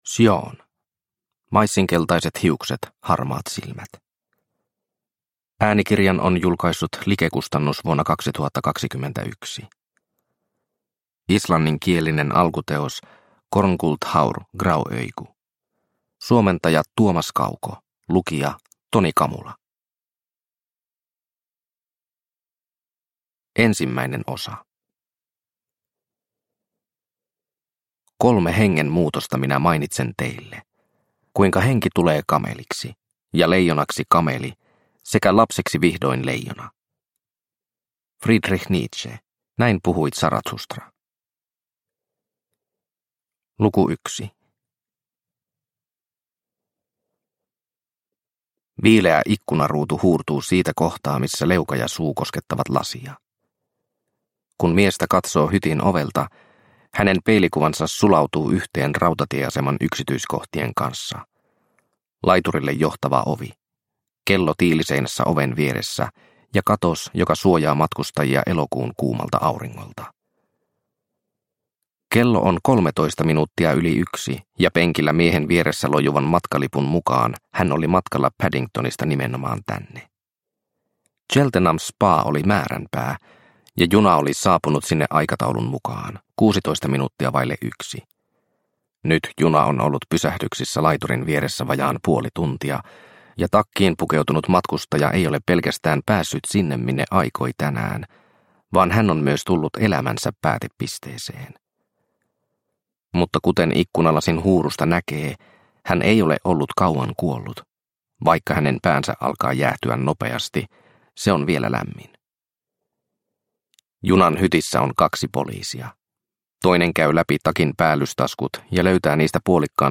Maissinkeltaiset hiukset, harmaat silmät – Ljudbok – Laddas ner